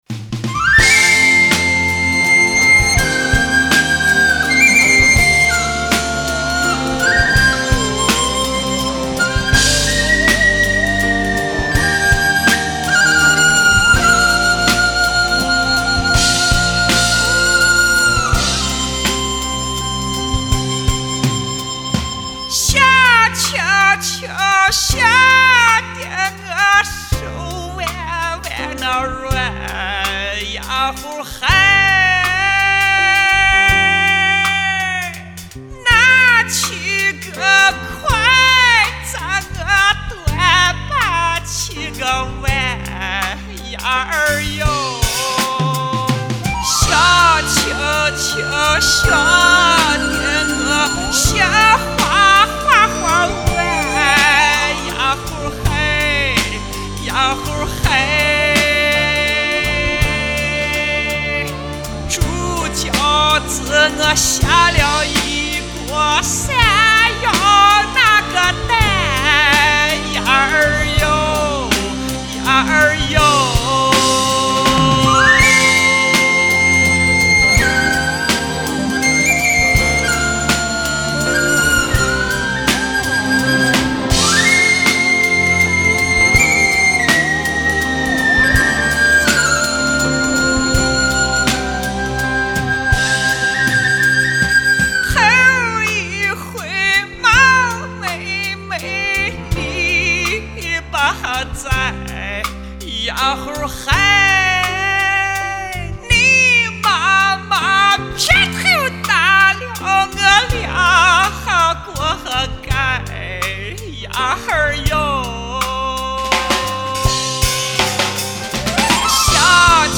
声音很特别
原汁原味的西部民歌，喜欢。